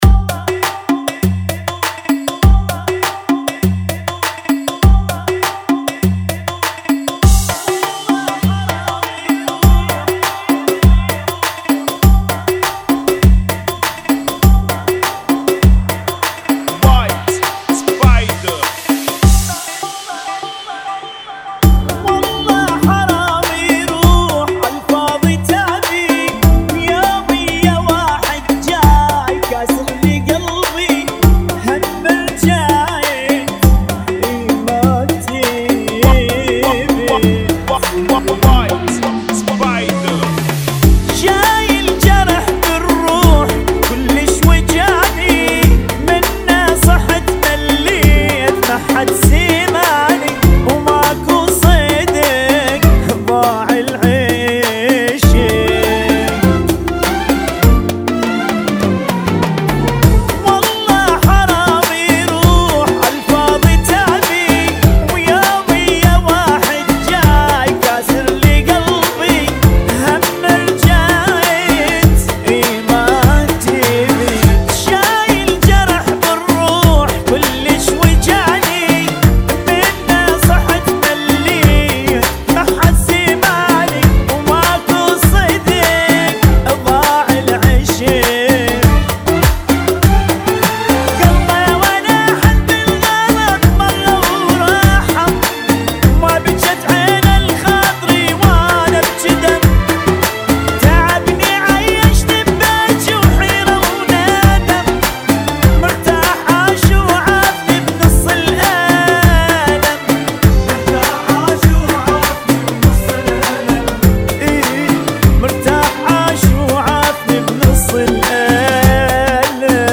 [ 100 Bpm ]
Funky